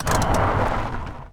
car-brakes-3.ogg